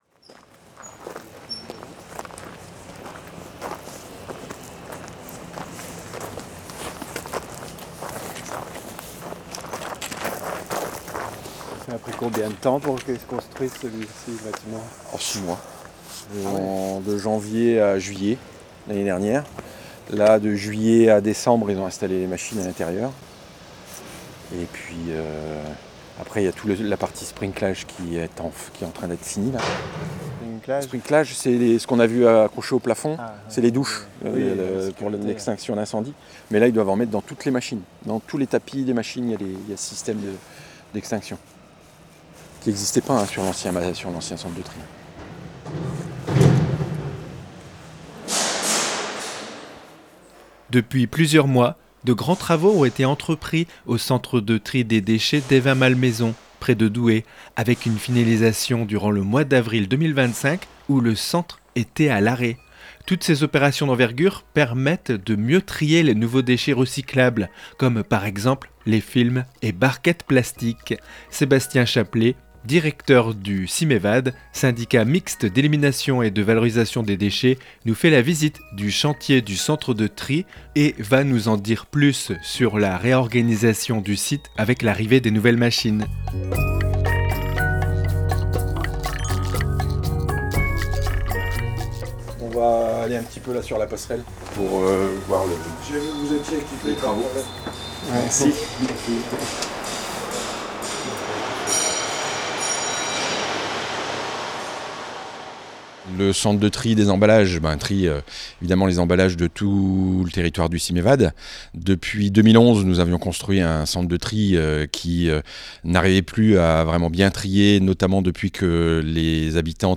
REPORTAGE-2504-De-grands-travaux-pour-un-centre-de-tri-plus-performant-a-Evin-Malmaison-SYMEVAD.mp3